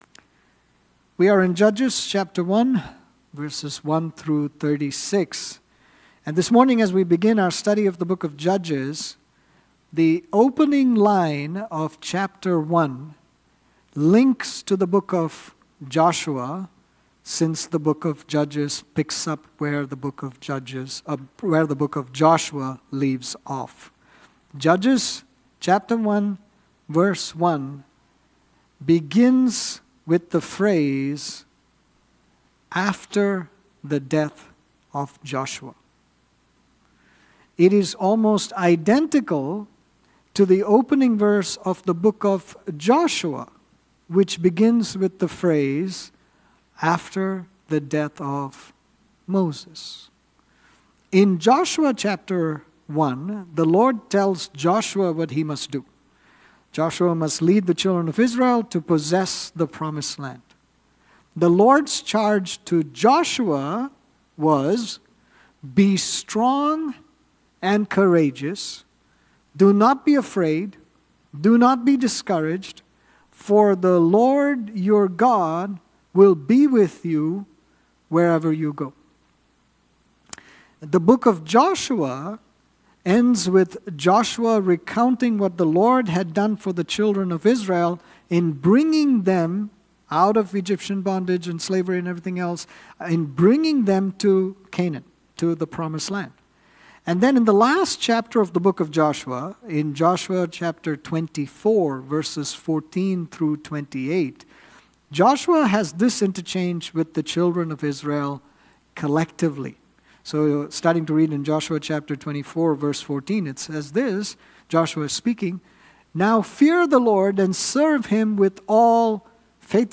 Sermons | New Life Fellowship Church